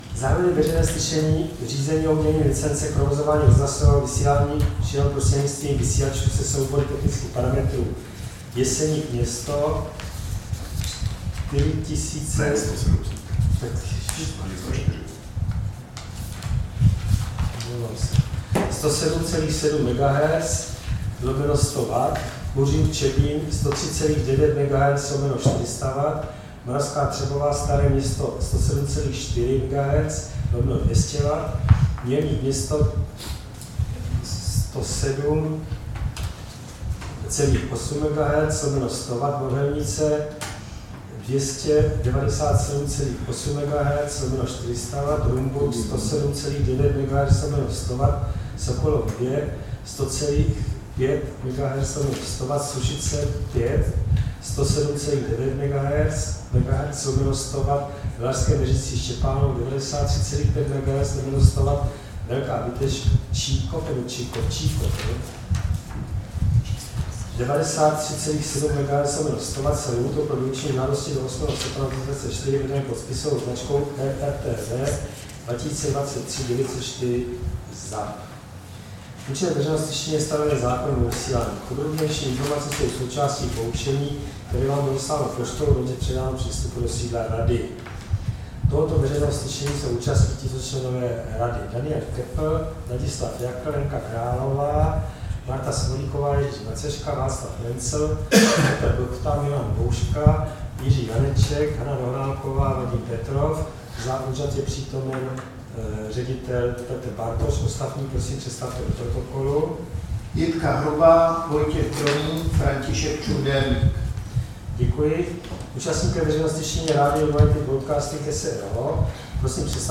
Veřejné slyšení v řízení o udělení licence k provozování rozhlasového vysílání šířeného prostřednictvím vysílačů se soubory technických parametrů Jeseník-město 107,7 MHz/100 W; Kuřim-Čebín 103,9 MHz/400 W; Moravská Třebová-Staré Město 107,4 MHz/200 W; Mělník-město 107,8 MHz/100 W; Mohelnice 97,8 MHz/400 W; Rumburk 107,9 MHz/100 W; Sokolov 100,5 MHz/100 W; Sušice 107,9 MHz/100 W; Valašské Meziříčí-Štěpánov 93,5 MHz/100 W; Velká Bíteš-Čikov 93,7 MHz/100 W
Místem konání veřejného slyšení je sídlo Rady pro rozhlasové a televizní vysílání, Škrétova 44/6, 120 00 Praha 2.